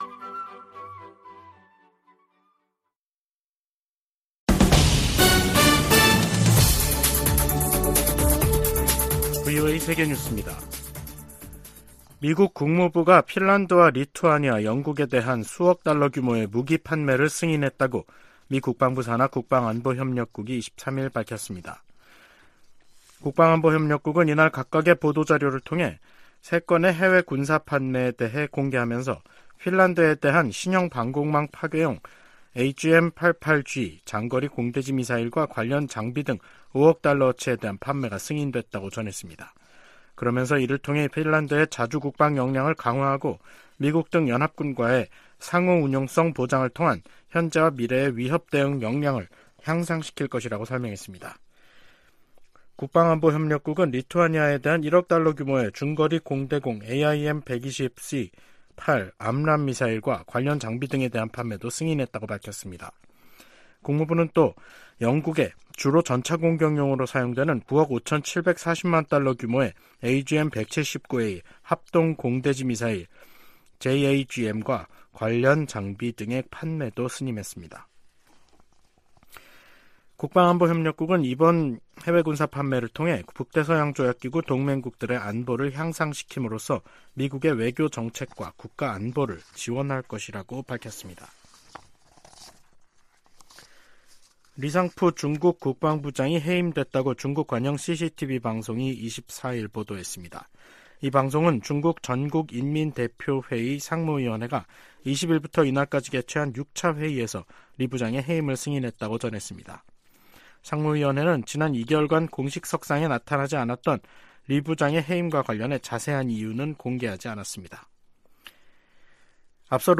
VOA 한국어 간판 뉴스 프로그램 '뉴스 투데이', 2023년 10월 23일 3부 방송입니다. 북한 주민 4명이 소형 목선을 타고 동해 북방한계선(NLL)을 통과해 한국으로 넘어 왔습니다. 미국 정부가 북한과 러시아의 무기 거래 현장으로 지목한 북한 라진항에 또다시 대형 선박이 정박한 모습이 포착됐습니다.